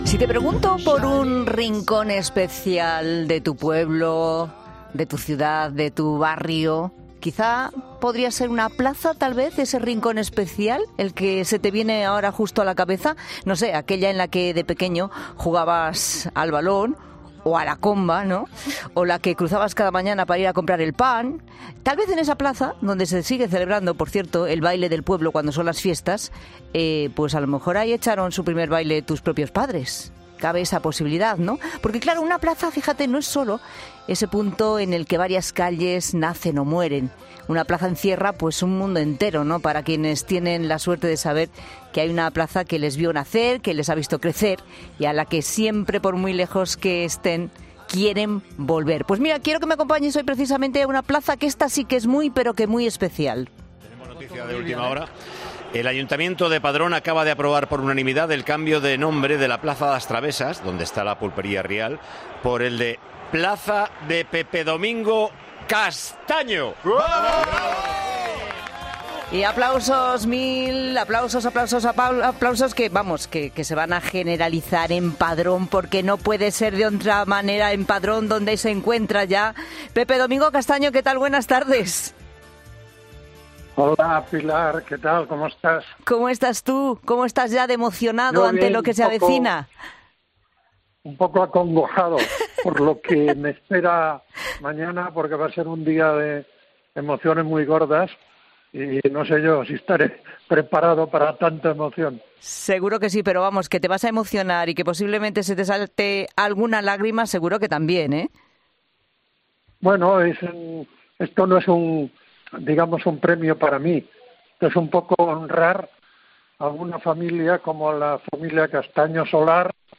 En el programa han hablado con él, y ha expresado cómo se siente un día antes de la inauguración: “Estoy un poco acongojado por lo que me espera mañana, va a ser un día lleno de emociones muy gordas".